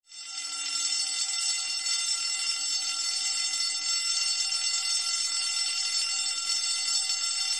callingbell.mp3